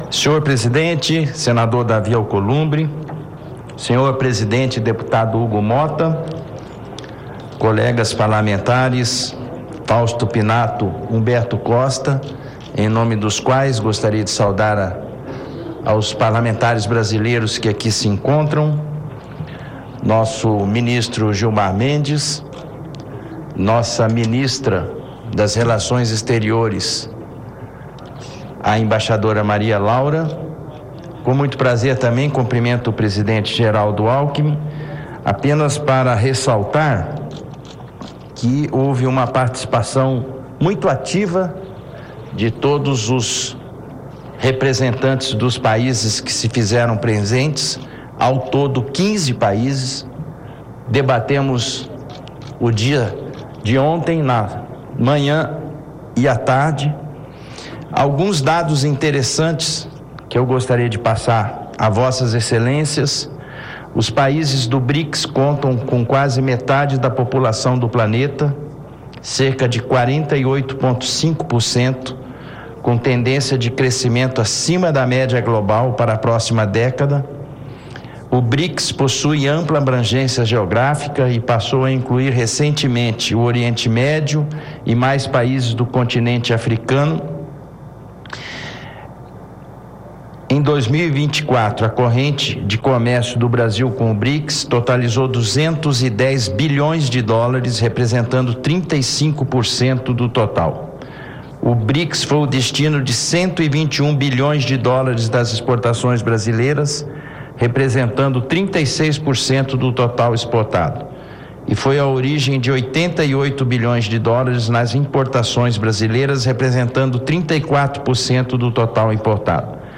Ouça a íntegra do discurso do presidente da Comissão de Relações Exteriores do Senado na abertura do 11º Fórum Parlamentar do Brics